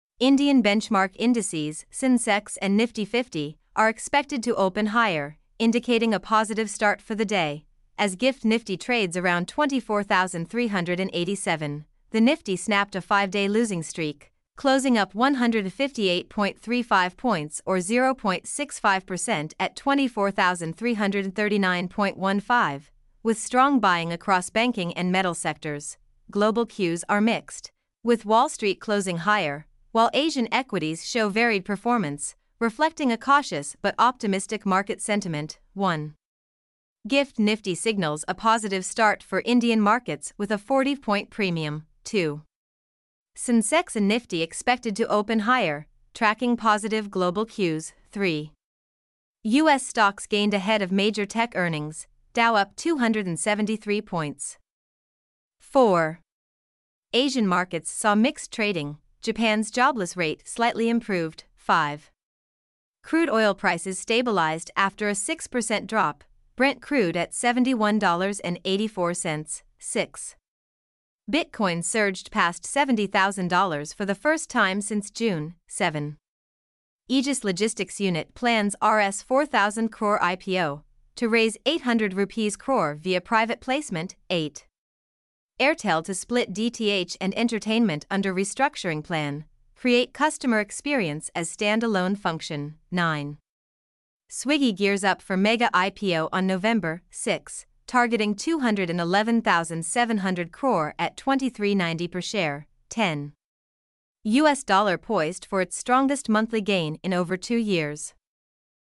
mp3-output-ttsfreedotcom-18.mp3